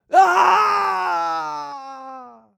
Male_Falling_Shout_03.wav